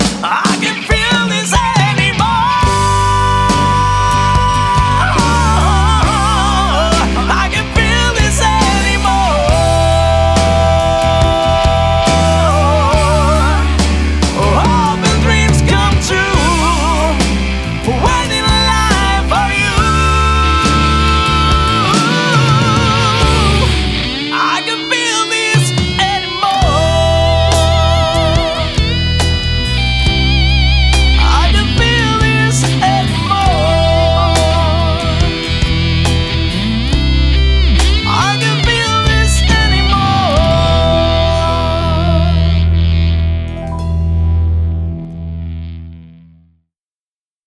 • Качество: 185, Stereo
красивые